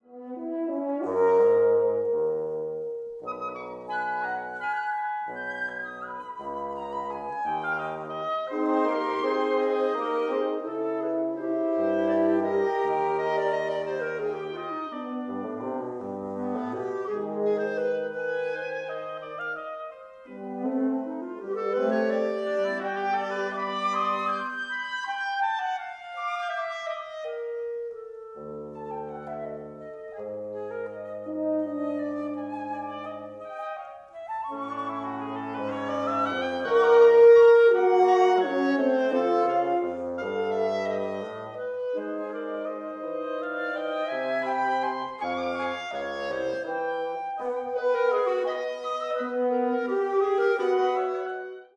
wind quintet (flute, oboe, clarinet, horn, bassoon)